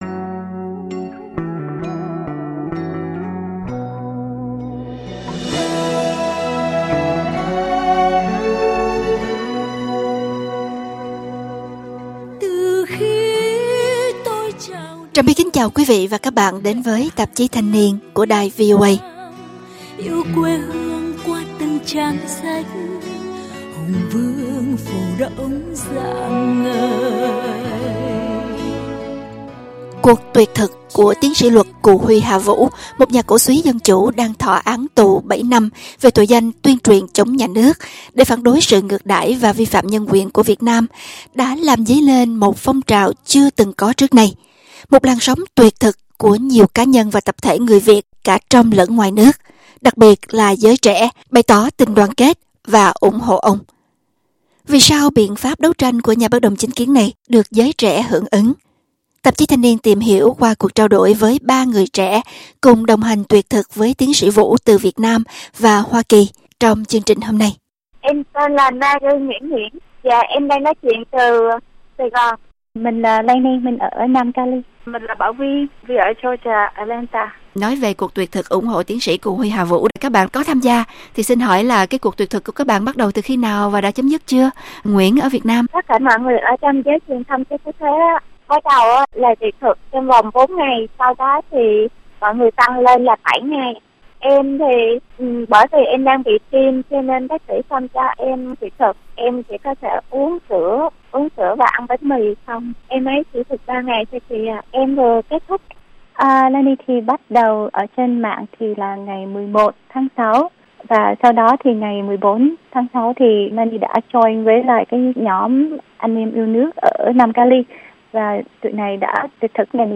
Vì sao biện pháp đấu tranh của nhà bất đồng chính kiến này được giới trẻ hưởng ứng? Tạp chí Thanh Niên tìm hiểu qua cuộc trao đổi với 3 người trẻ cùng đồng hành tuyệt thực với Tiến sĩ Vũ từ Việt Nam và Hoa Kỳ trong chương trình hôm nay.